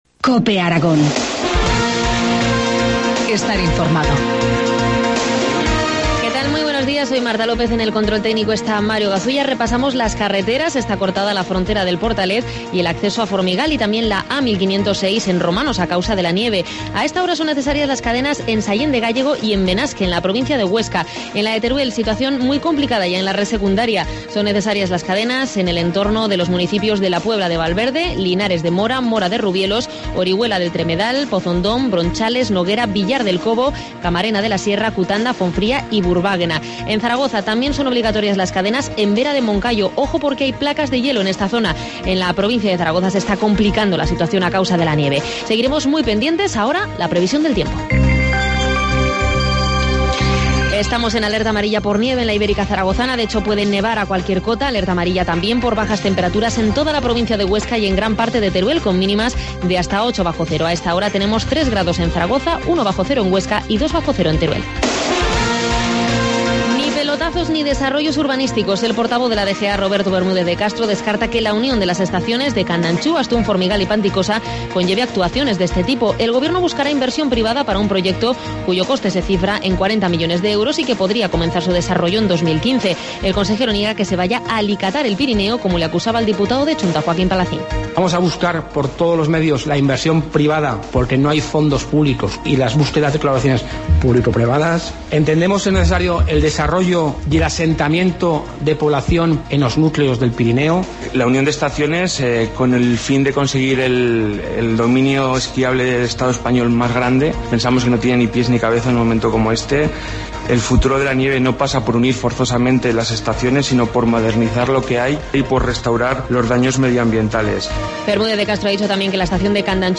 Informativo matinal, martes 26 de febrero, 8.25 horas